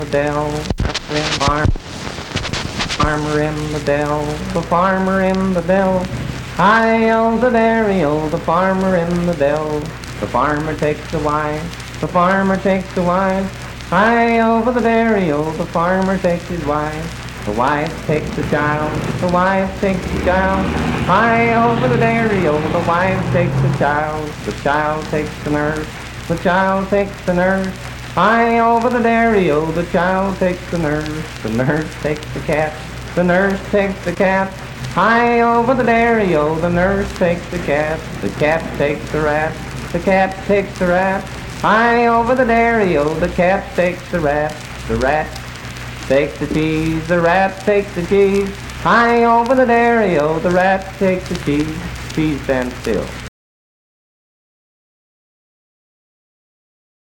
Unaccompanied vocal performance
Dance, Game, and Party Songs, Children's Songs
Voice (sung)
Spencer (W. Va.), Roane County (W. Va.)